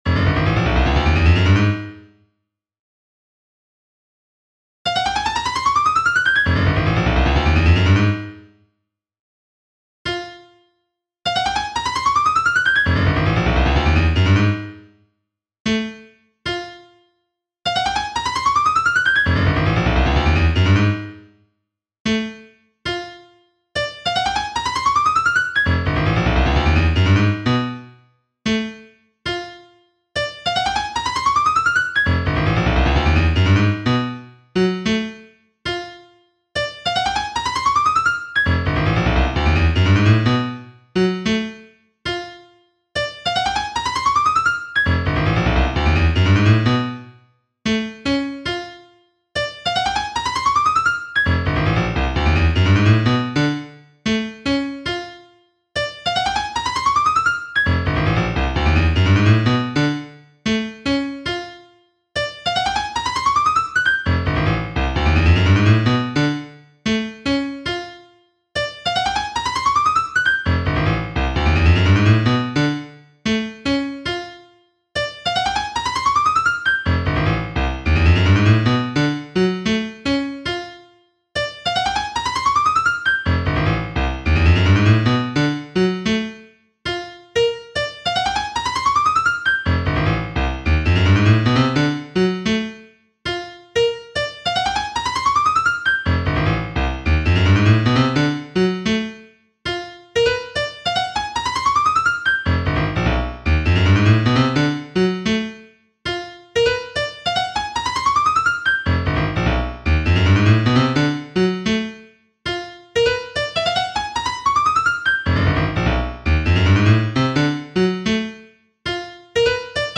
Sonification 2: Pauses for absent pieces
This creates a bit more variety in when notes sound, and thus a bit less monotonous. To my mind this sounds a bit more interesting, like a demented John Cage: